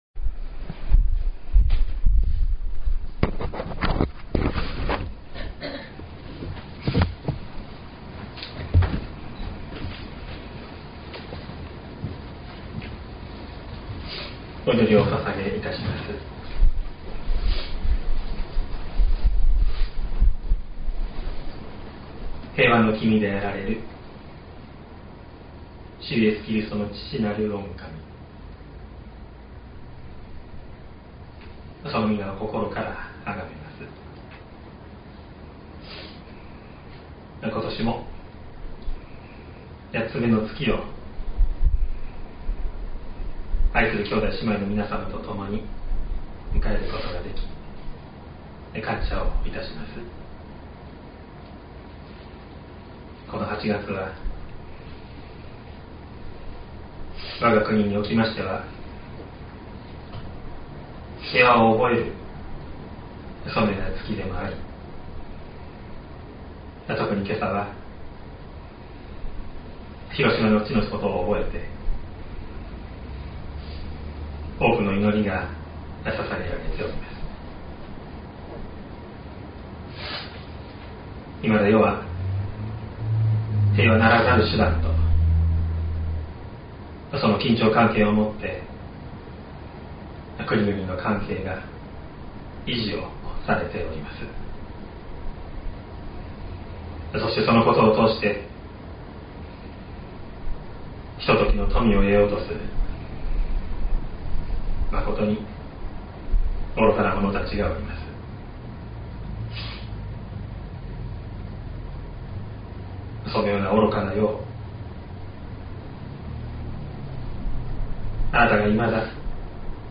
2023年08月06日朝の礼拝「「神のはかり」」西谷教会
音声ファイル 礼拝説教を録音した音声ファイルを公開しています。